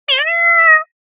ミャ〜と可愛い声で鳴く猫の声。